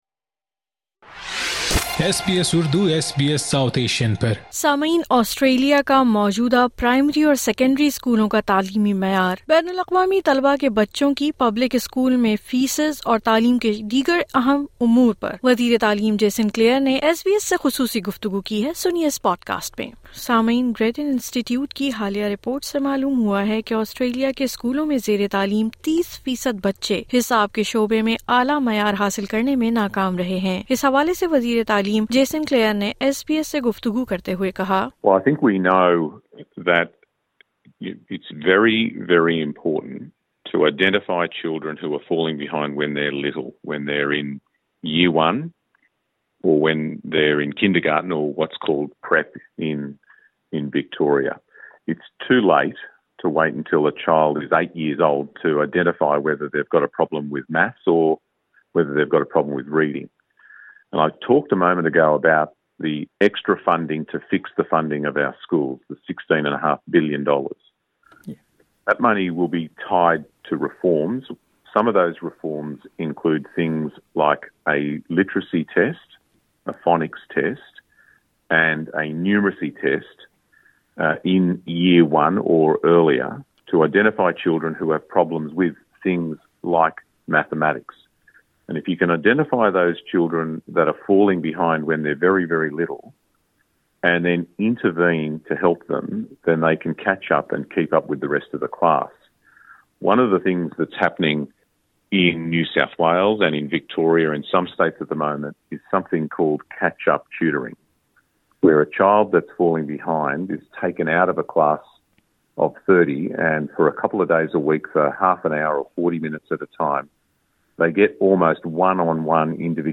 آسٹریلین اسکولوں میں تعلیم کے مسائل، عارضی ویزہ رکھنے والے والدین کے بچوں کی اسکول فیس اور اسکولوں میں مختلف ثقافتی پس منظر کے باعث تضحیک کا نشانہ بنایا جانے سمیت اہم امور پر وزیر تعلیم جیسن کلیئر کی ایس بی ایس سے خصوصی گفتگو سنئے اس پوڈکاسٹ میں